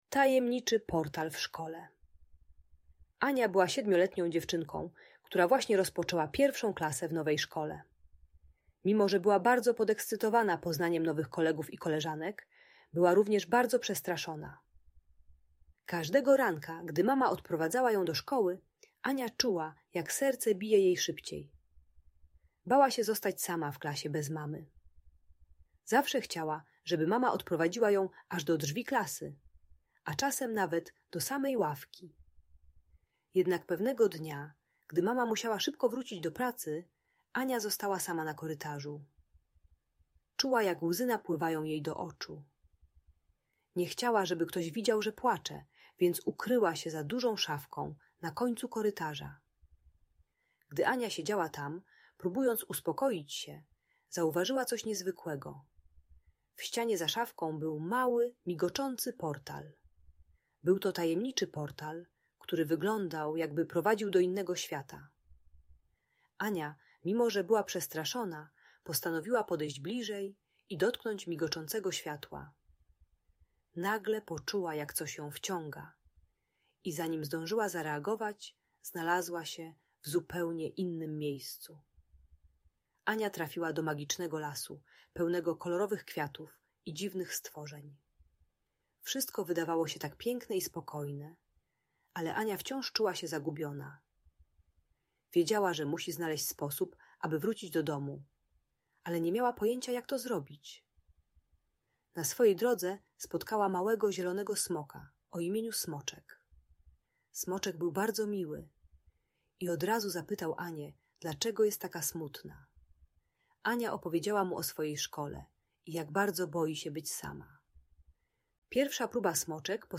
Tajemniczy Portal w Szkole - Audiobajka dla dzieci